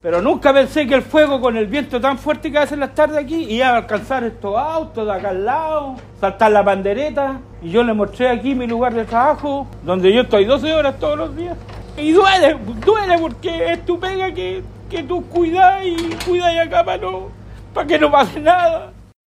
Dependencias del centro de acopio y reciclaje también se vieron afectadas, según lamentó uno de los trabajadores.